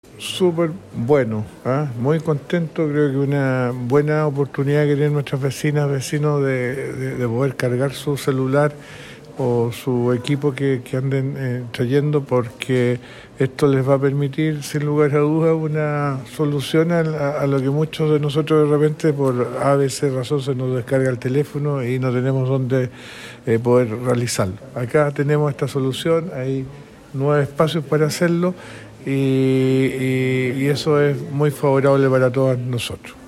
Audio-Rolando-Mitre-alcalde-Mariquina-1.mp3